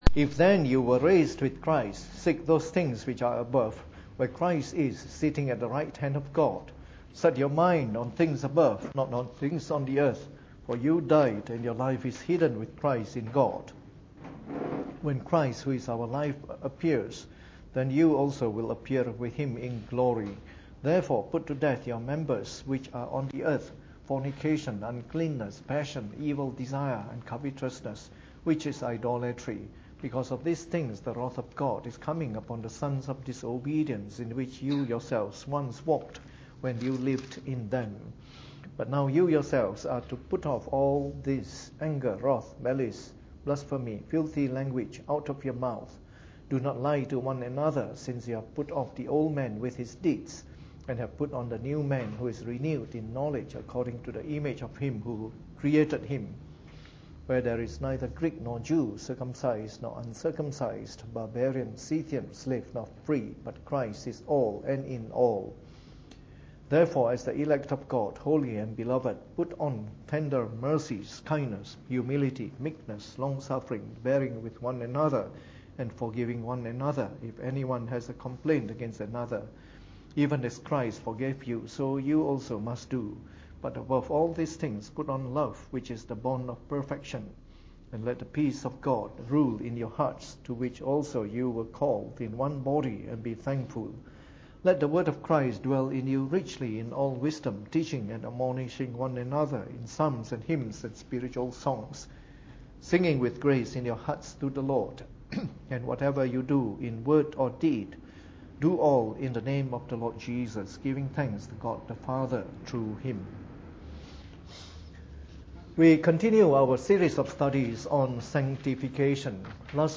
Preached on the 21st of January 2015 during the Bible Study, from our new series of talks on Sanctification.